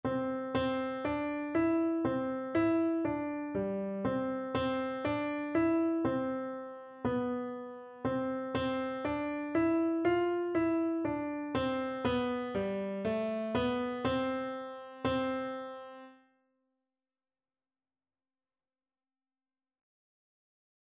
Traditional Trad. Yankee Doodle Piano version
Traditional Music of unknown author.
C major (Sounding Pitch) (View more C major Music for Piano )
4/4 (View more 4/4 Music)
Instrument: